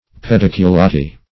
Pediculati \Pe*dic`u*la"ti\, prop. n. pl. [NL. See Pedicle.]